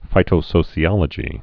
(fītō-sōsē-ŏlə-jē, -shē-)